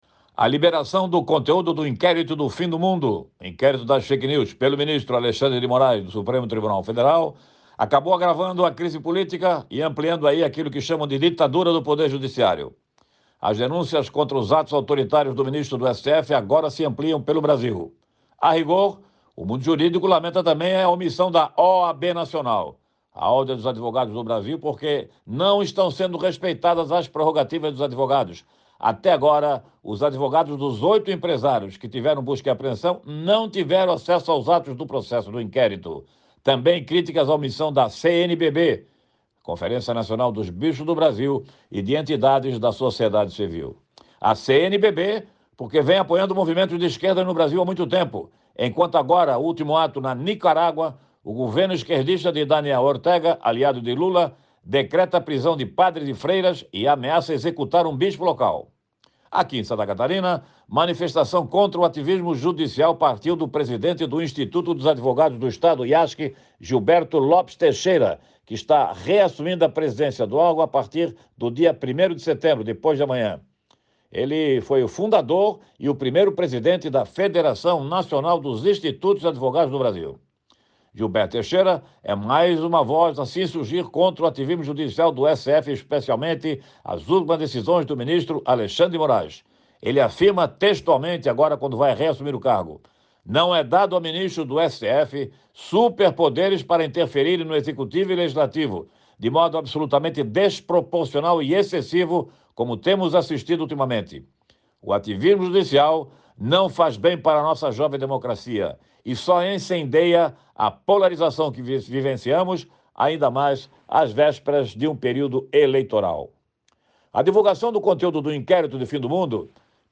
Jornalista destaca as manifestações em Santa Catarina contra o ativismo judicial